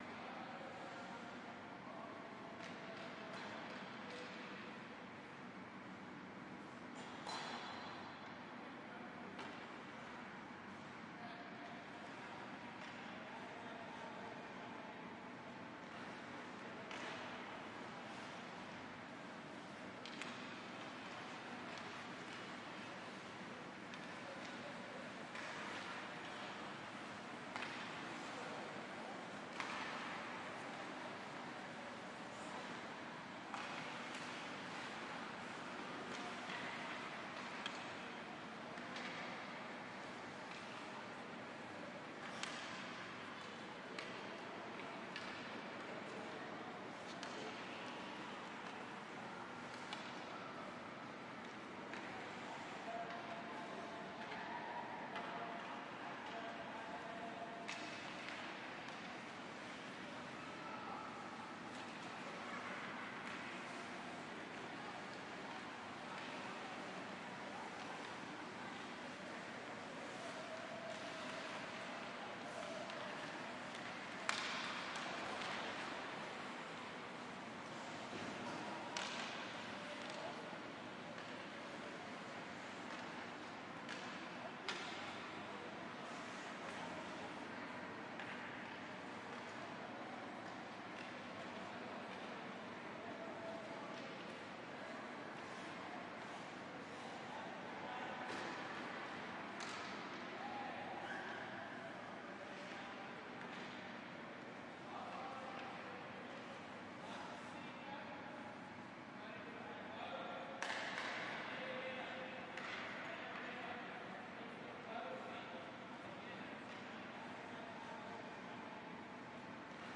滑冰的声音
描述：在冰冻的湖面上滑冰的声音。
Tag: 溜冰 速度滑冰 冬季 氛围 冰冻